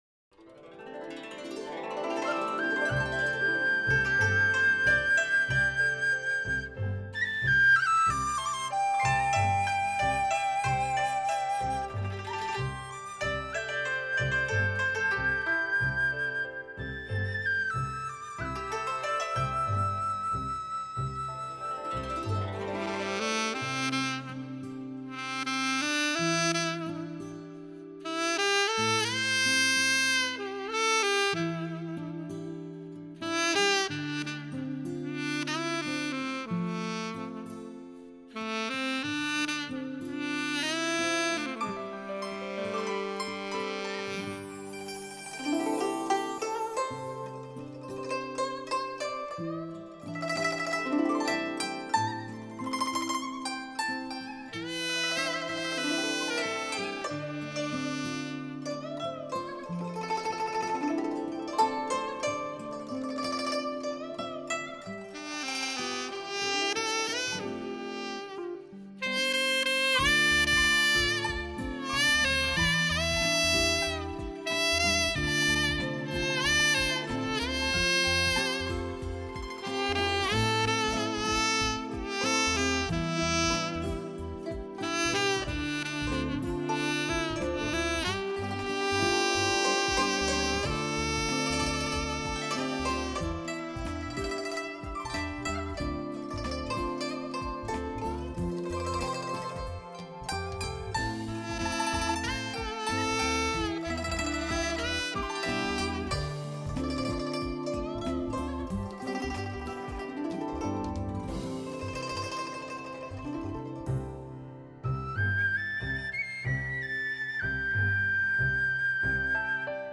将古筝与萨克斯结合起来，由国内外大师合奏，
将萨克斯的哀伤，古筝的惆怅表现得淋漓尽致
两大乐器完美的相融合，经典而又感性。